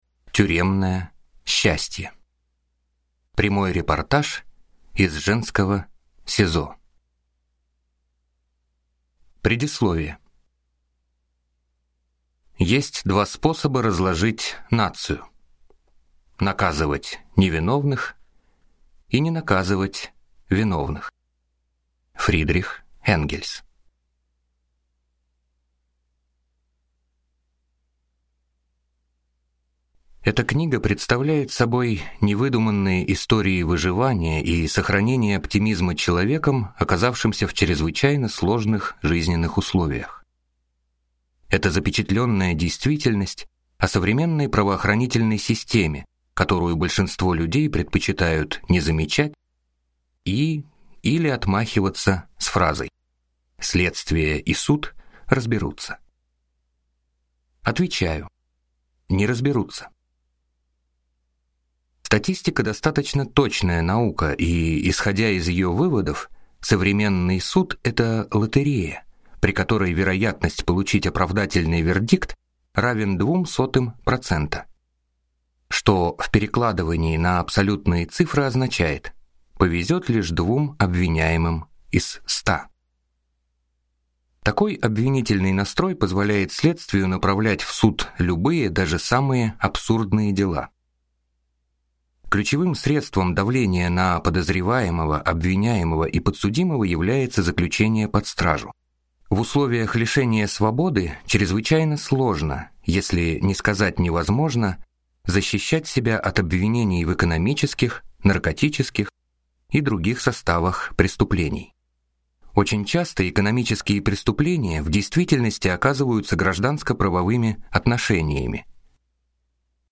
Аудиокнига Тюремное счастье | Библиотека аудиокниг